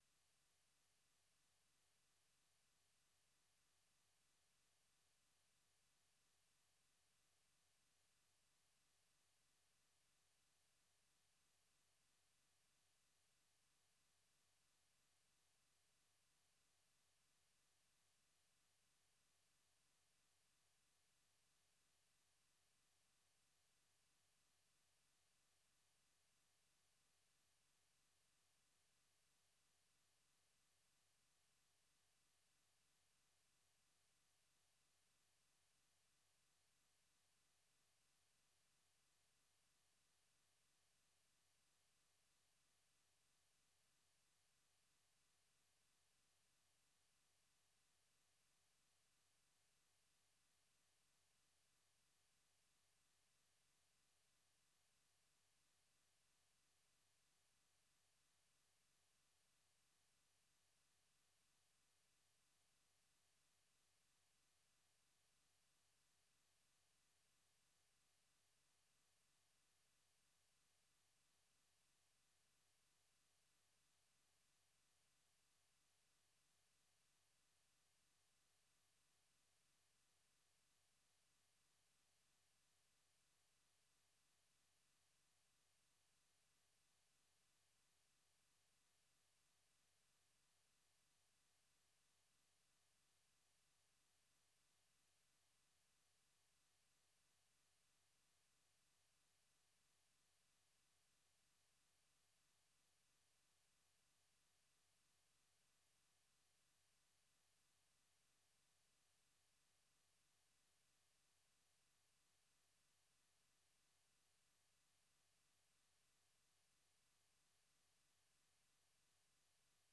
Idaacadda Galabnimo waxaad ku maqashaan wararka ugu danbeeya ee caalamka, barnaamijyo, ciyaaro, wareysiyo iyo waliba heeso.